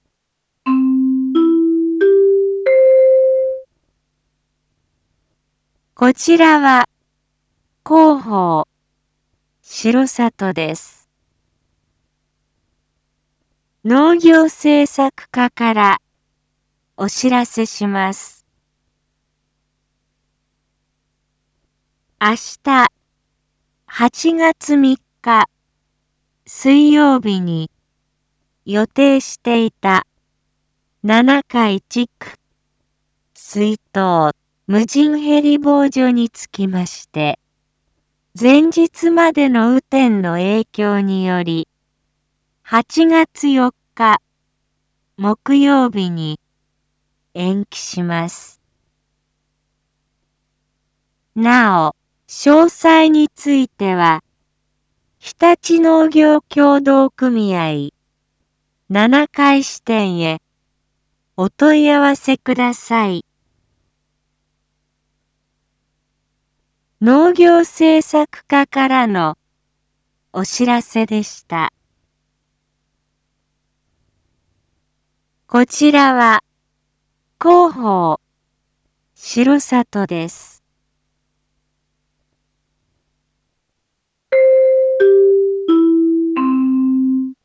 一般放送情報
Back Home 一般放送情報 音声放送 再生 一般放送情報 登録日時：2022-08-02 07:06:28 タイトル：無人ヘリ防除延期について（七会地区限定） インフォメーション：こちらは広報しろさとです。